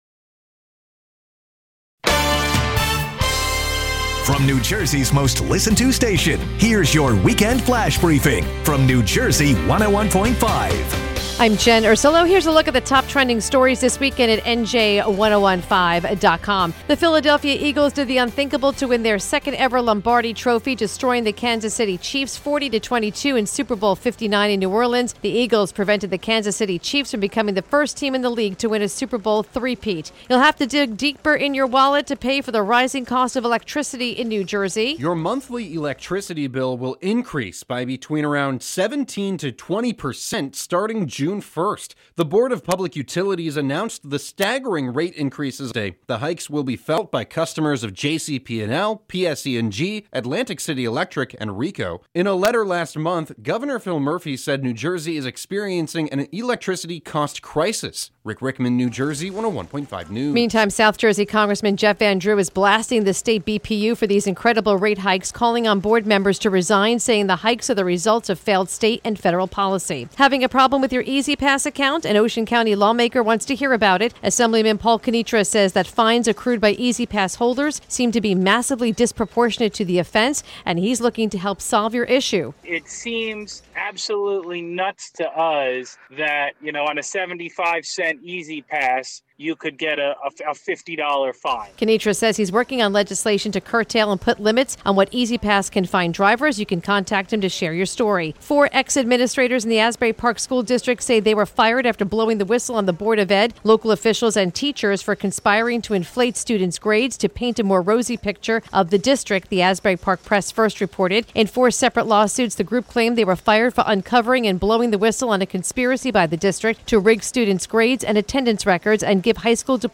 The latest New Jersey news and weather from New Jersey 101.5 FM, updated every hour, Monday through Friday.